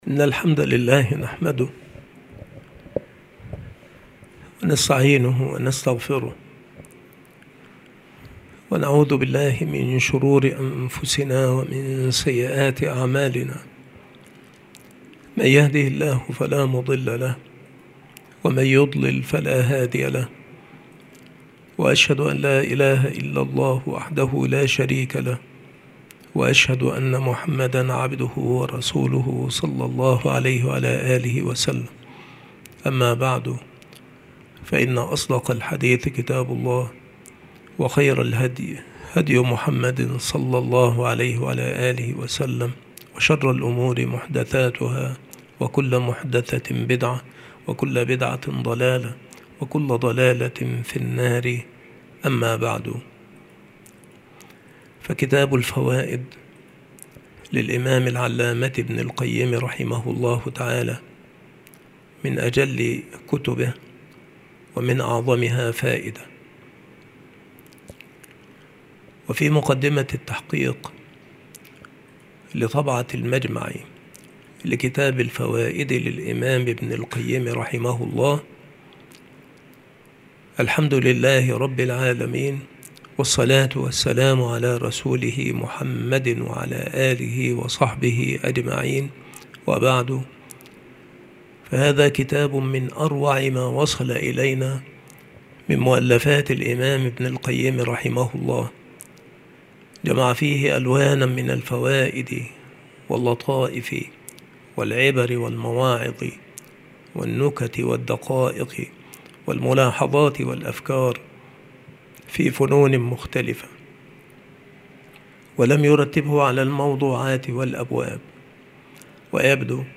مكان إلقاء هذه المحاضرة المكتبة - سبك الأحد - أشمون - محافظة المنوفية - مصر عناصر المحاضرة : المقدمة. معرفة الله نوعان. الفوائد في عُرف المؤلفين. قاعدة جليلة إذا أردت الانتفاع بالقرآن فاجمع قلبك عند تلاوته وسماعه. عين اليقين نوعان. من الأسرار والعبر في آية النور.